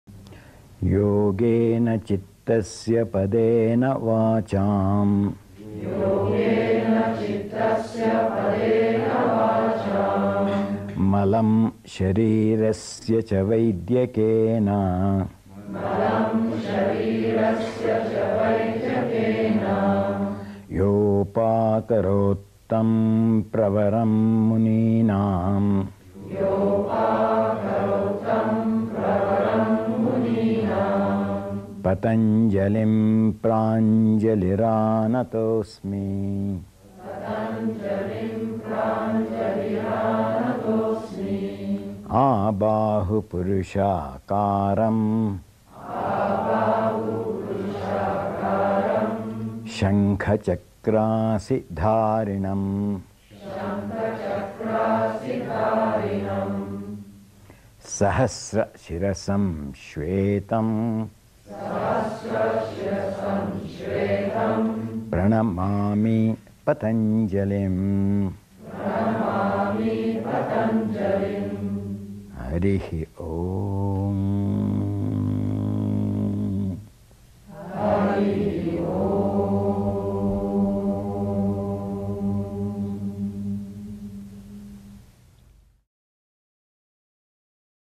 audio recording of Guruji BKS Iyengar chanting the Invocation to Patanjali
Invocation-to-Patanjali-by-BKS-Iyengar.mp3